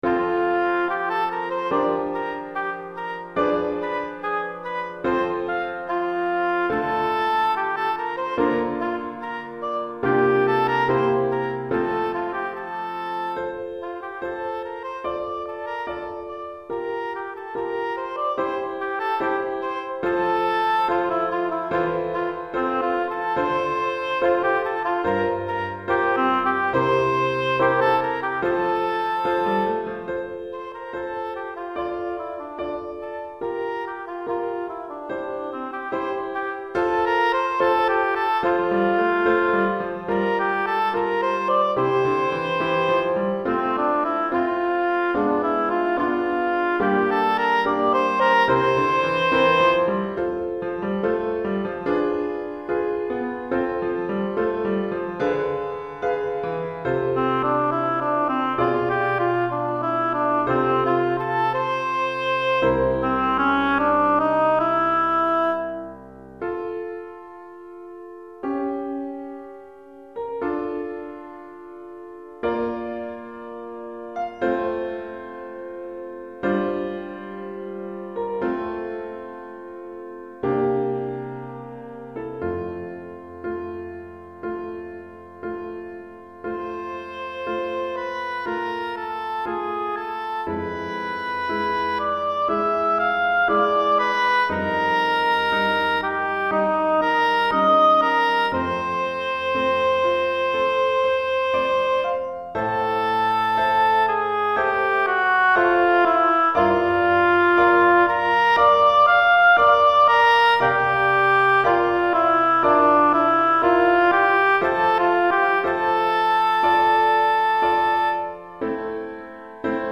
Pour hautbois et piano DEGRE CYCLE 2